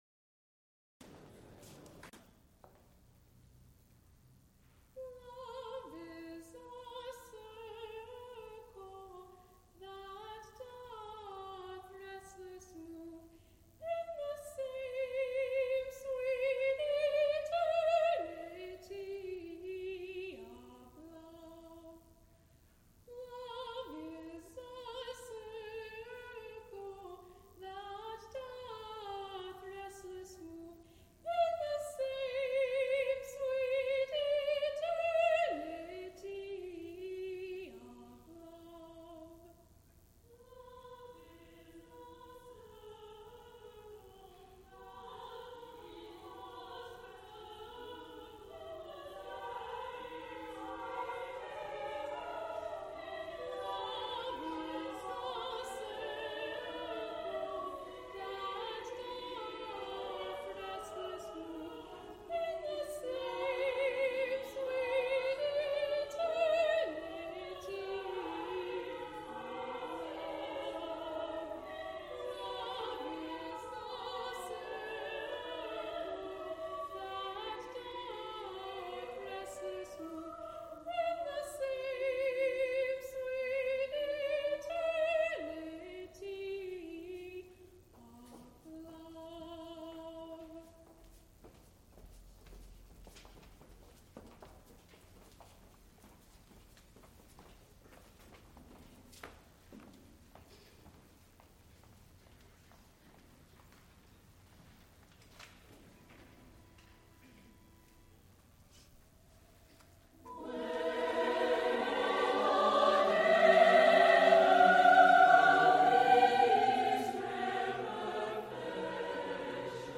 transcribed for chorus
mezzo-sporano
Heinz Chapel Choir
Recorded live November 11, 1979, Heinz Chapel, University of Pittsburgh.
Extent 2 audiotape reels : analog, quarter track, 7 1/2 ips ; 12 in.
musical performances
Madrigals, English Choruses, Sacred (Mixed voices), Unaccompanied